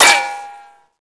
metalsolid2.wav